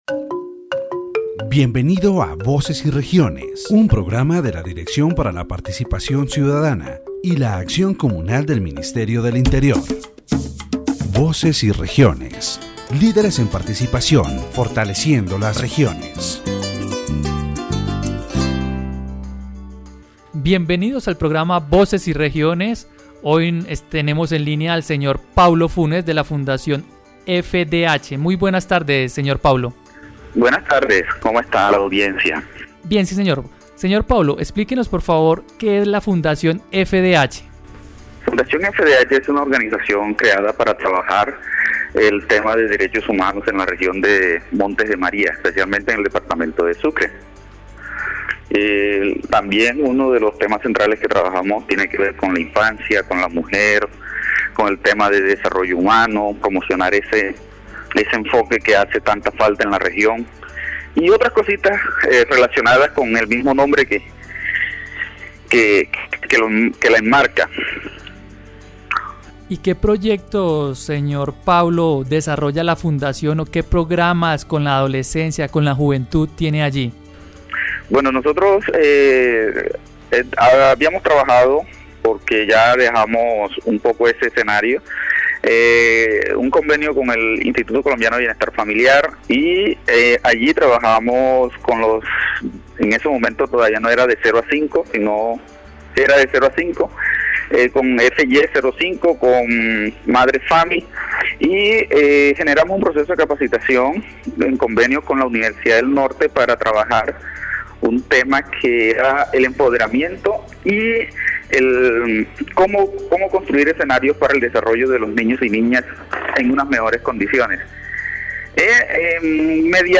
In this section of Voces y Regiones, the interviewee, a member of the FDH Foundation, shares the story and mission of the organization, emphasizing its human-centered approach and commitment to women's empowerment. The foundation was created as a response to the social needs of vulnerable communities, promoting equity, inclusion, and capacity-building. A key aspect of their work involves training programs carried out in partnership with the Universidad del Norte.